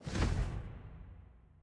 minecraft / sounds / mob / phantom / flap1.ogg
flap1.ogg